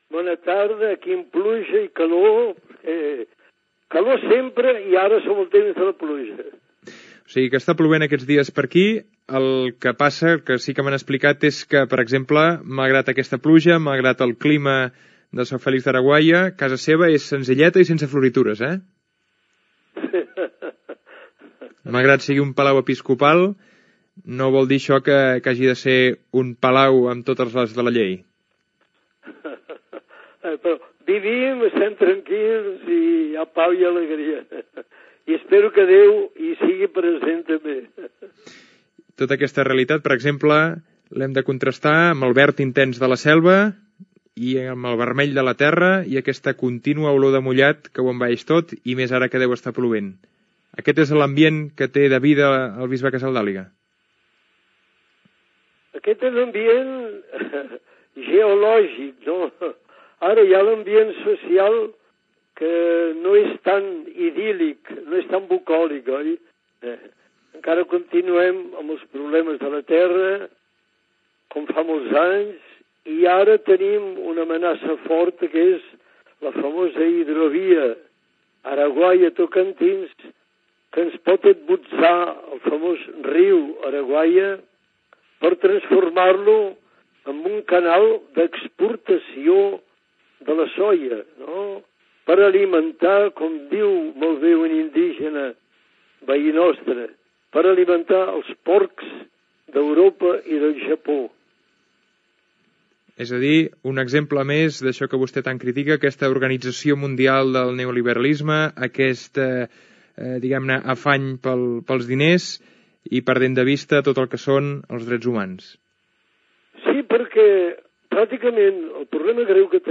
Entrevista Pere Casàldaliga, bisbe de Sao Felix do Araguaia, de l'estat de Mato Grosso, al Brasil, coincidint amb la campanya de Mans Unides "Fem del món la terra de tothom" en defensa dels sense terra